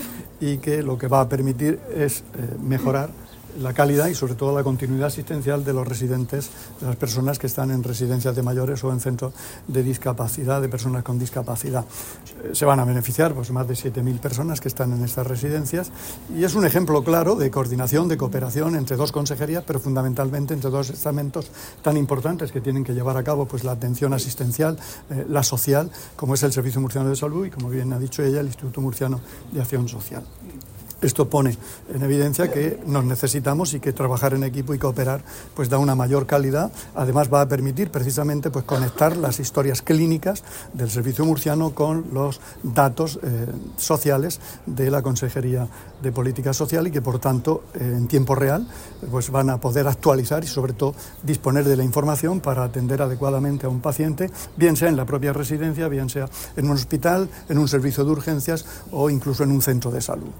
Declaraciones del consejero de Salud, Juan José Pedreño, [mp3]  y de la